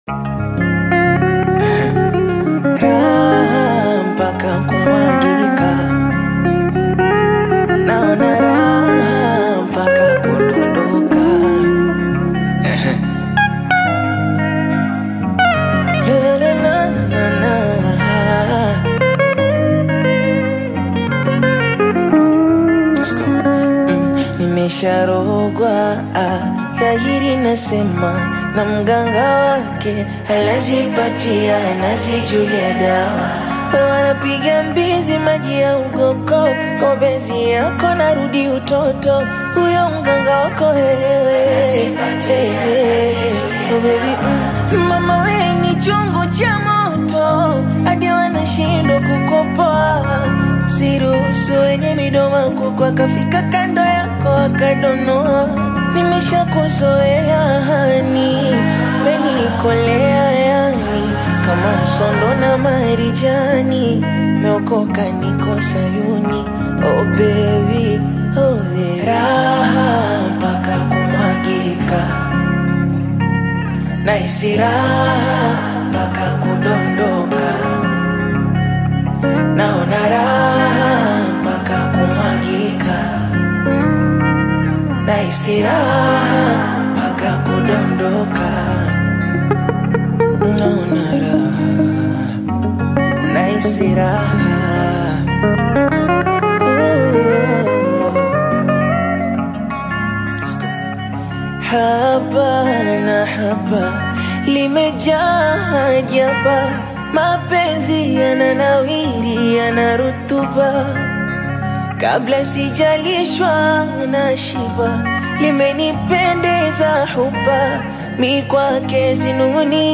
stripped-down Bongo Flava/acoustic rendition
Genre: Bongo Flava